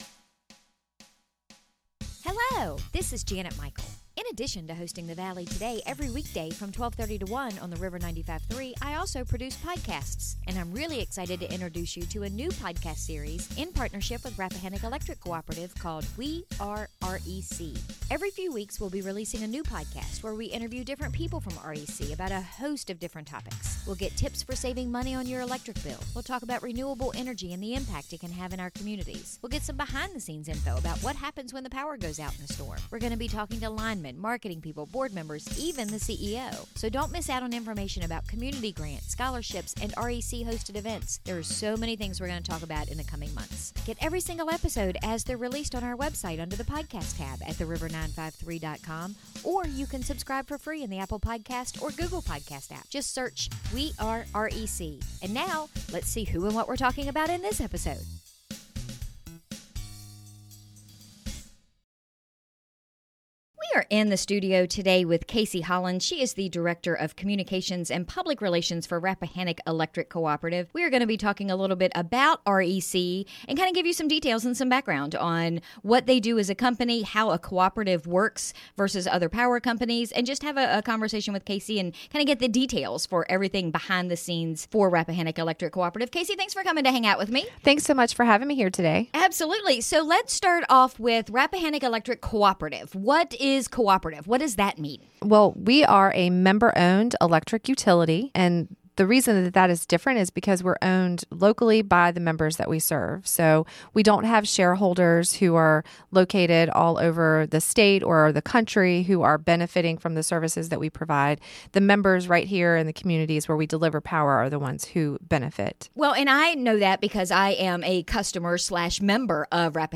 Every few weeks, we’ll be releasing a new podcast where we interview different people from REC about a host of different topics.